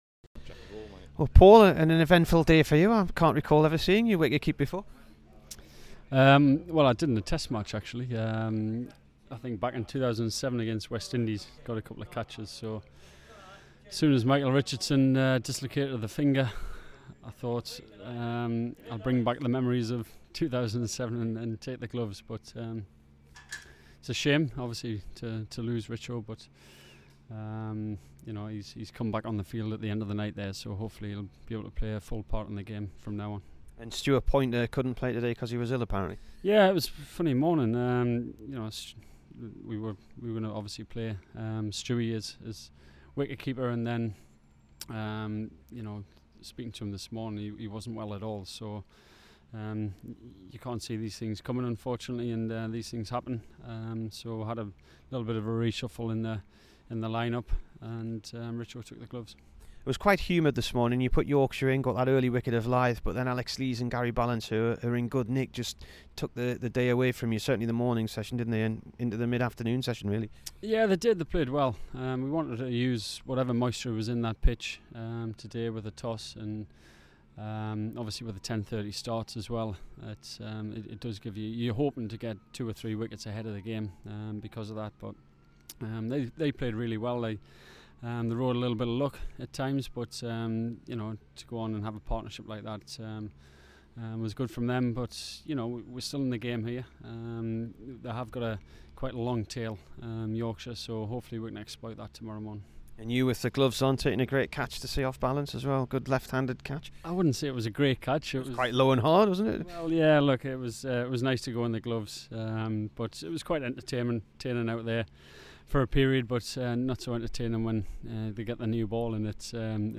Here's the Durham skipper after day one at Headingley.